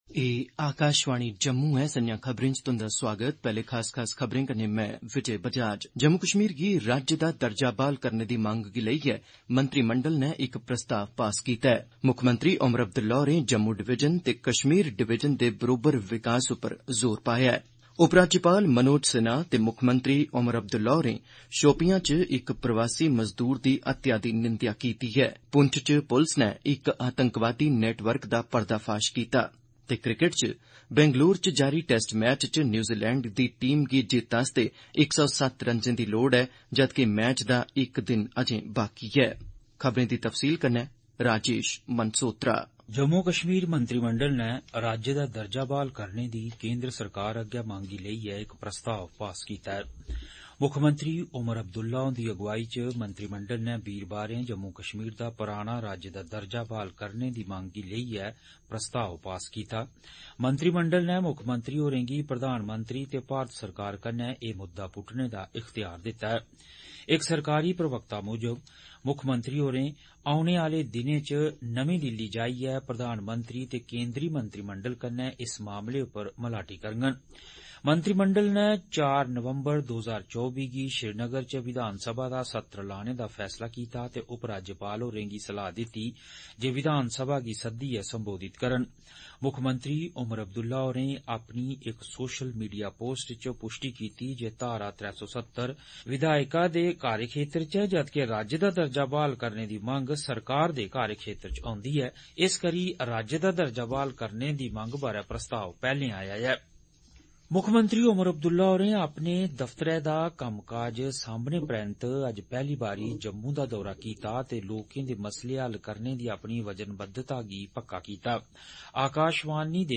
Transcript summary Play Audio Evening News
Dogri-News.mp3